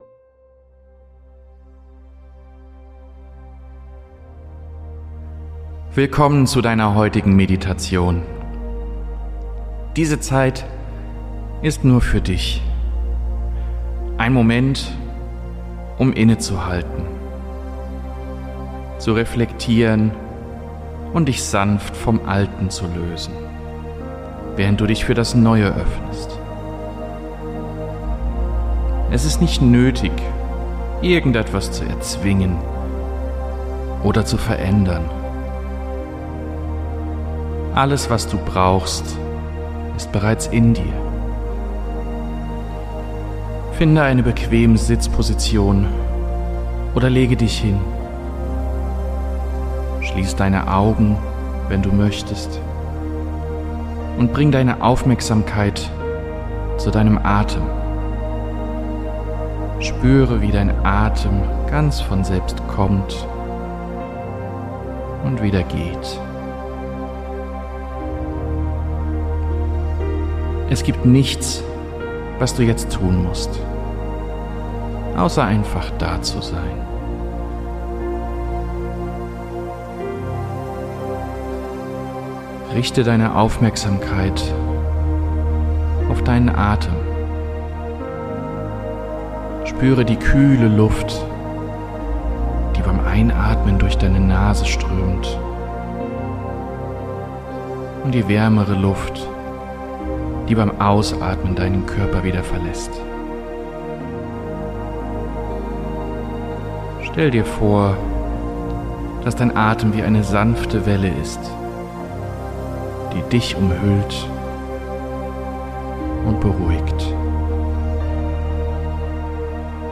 Der Jahreswechsel ist eine Zeit der Reflexion und des Neubeginns. Diese geführte Meditation hilft dir, alte Lasten loszulassen, inneren Frieden zu finden und dich voller Hoffnung und Zuversicht auf das neue Jahr auszurichten.